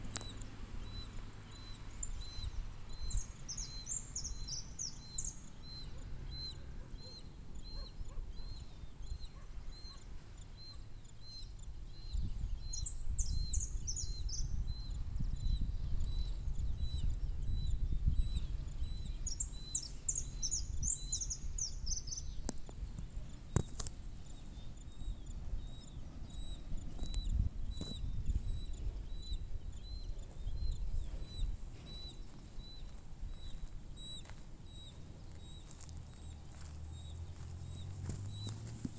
Gavilán Mixto (Parabuteo unicinctus)
Tres individuos. Un adulto un juvenil y otro escuchado
Localización detallada: Entre santa maria y amaicha del valle
Condición: Silvestre
Certeza: Fotografiada, Vocalización Grabada